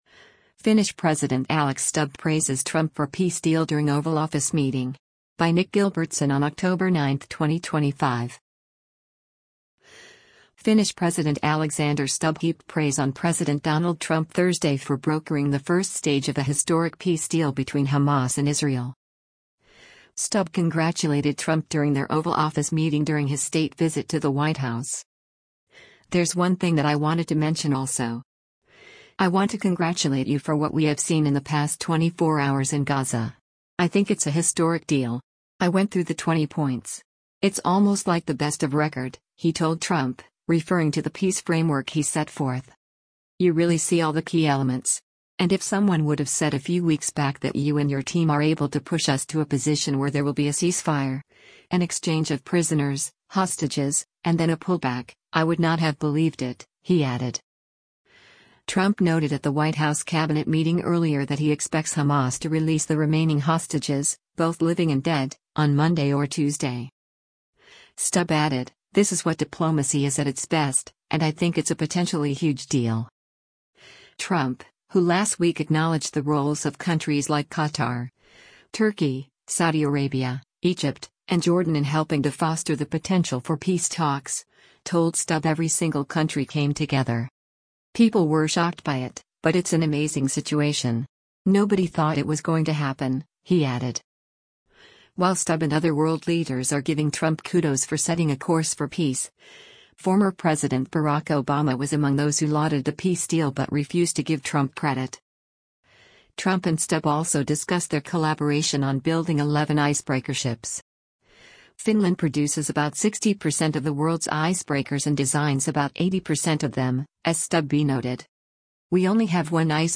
Finnish President Alex Stubb Praises Trump for Peace Deal During Oval Office Meeting
Stubb congratulated Trump during their Oval Office meeting during his state visit to the White House.